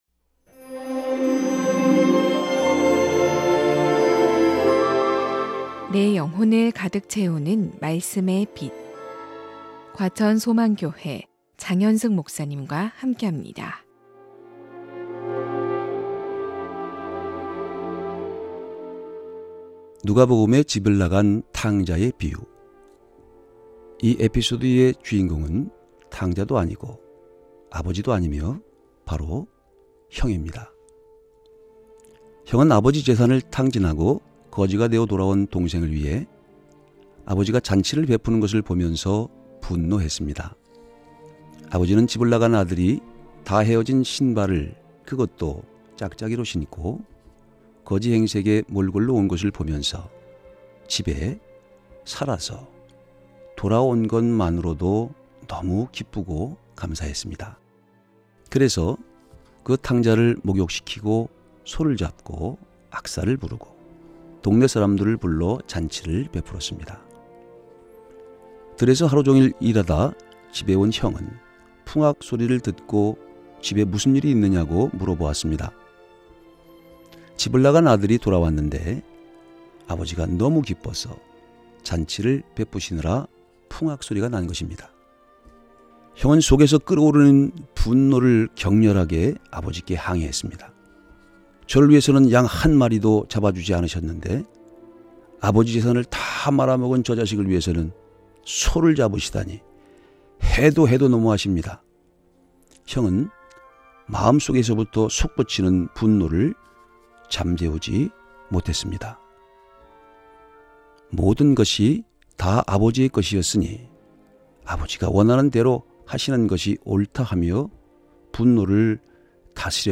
극동방송 설교 녹음파일 모아 듣기